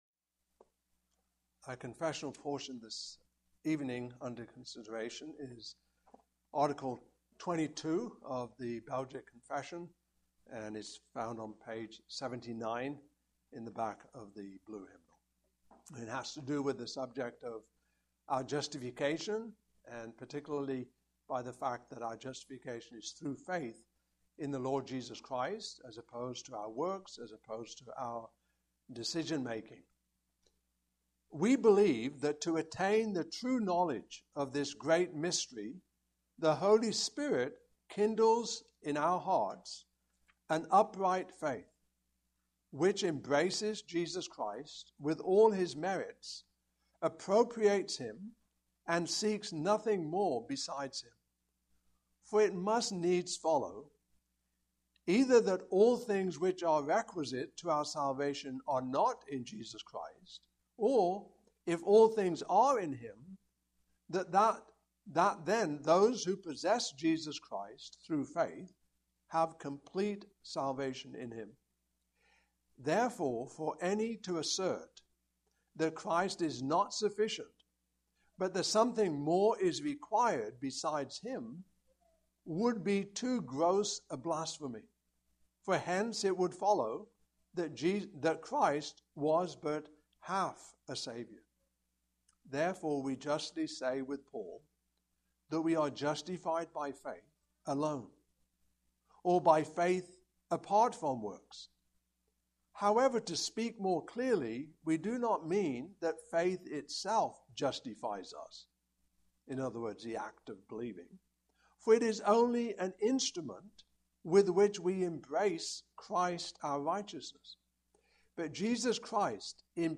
Belgic Confession 2025 Passage: Romans 4:1-25 Service Type: Evening Service Topics